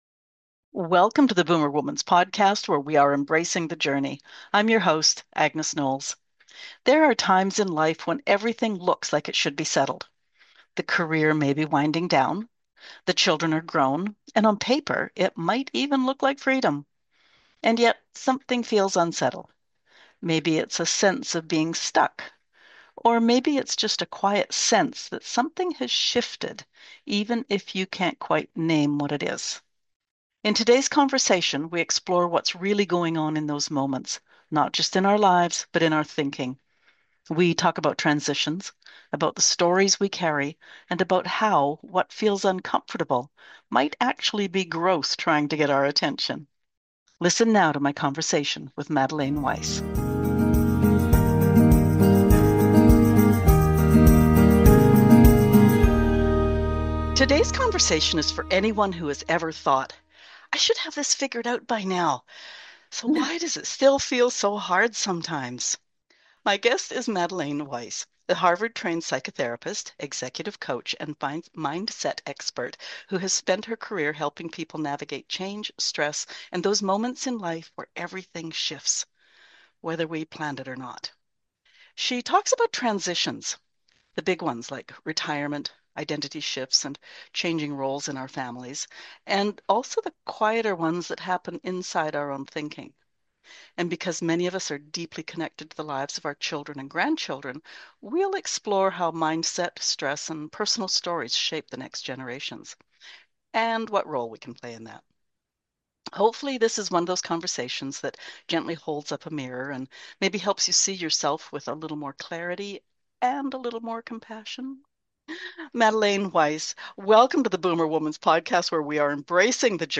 This is a conversation that invites you to slow down, listen inward, and reconsider what growth might actually look like at this stage of life.